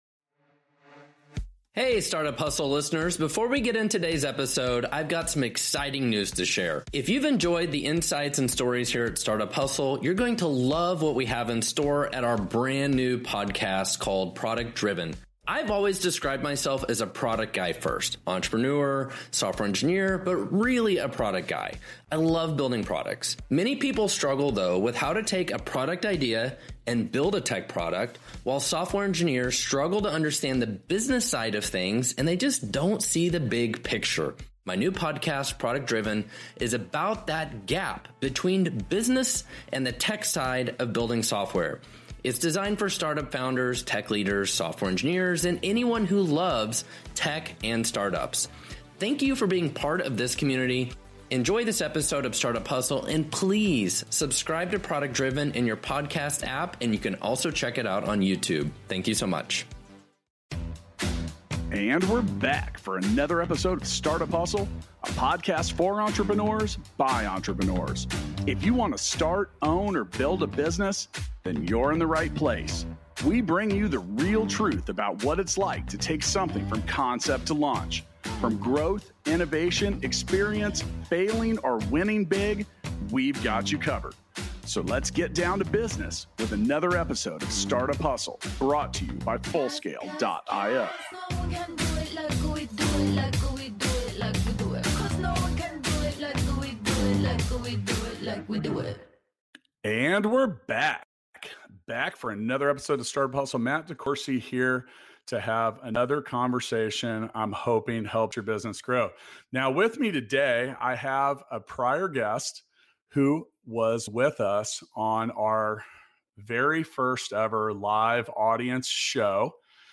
A Casual Chat